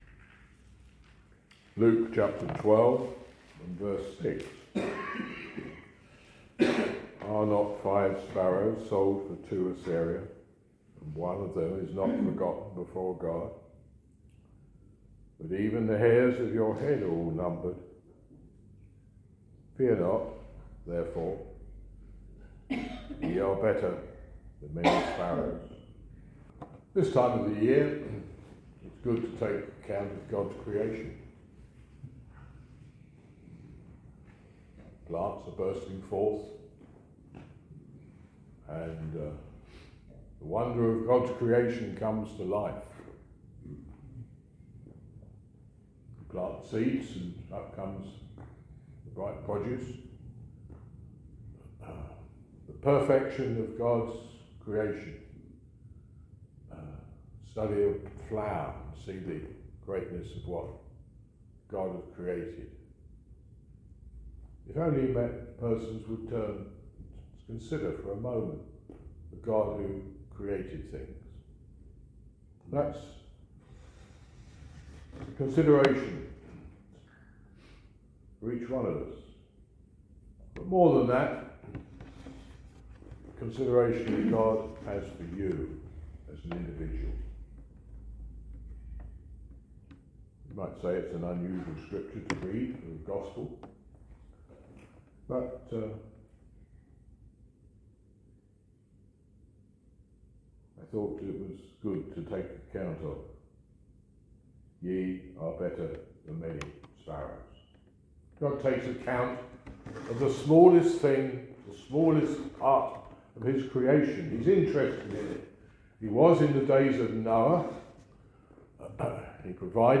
This sermon encourages us to trust in God's love, knowing He has not overlooked our need for salvation.